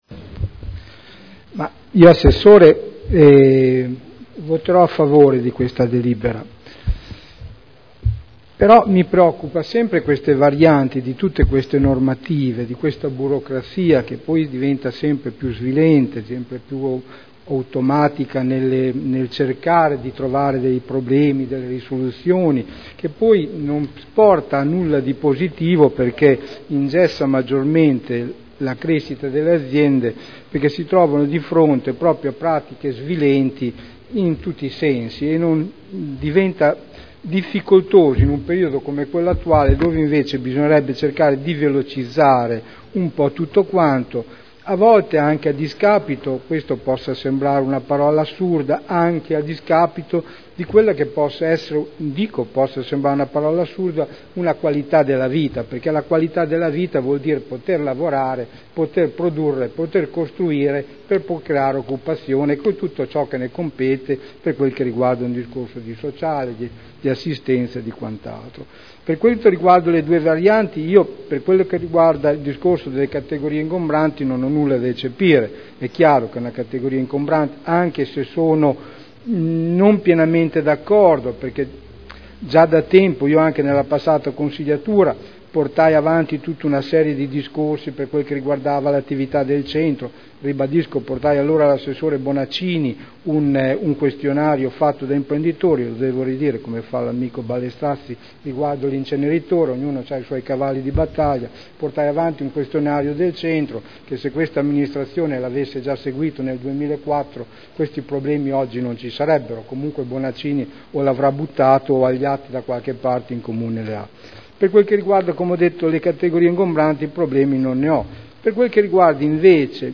Sergio Celloni — Sito Audio Consiglio Comunale
Dibattito su proposta di deliberazione: Variante al POC e al RUE relativamente a pubblici esercizi e merceologie ingombranti, aggiornamento della disciplina degli immobili con codici ISTAR-ATECO 2002 – Approvazione